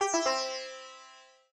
sitar_gec.ogg